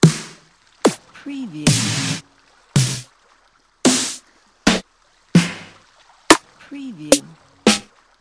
سمپل پک اسنیر Snare | دانلود 2 هزار سمپل Snare یکجا
سمپل پک اسنیر Snare | دانلود 2000 سمپل با کیفیت از snare دسته بندی شده در سبک ها و استایل های مختلف از هنر صدا
demo-snare.mp3